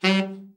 TENOR SN  12.wav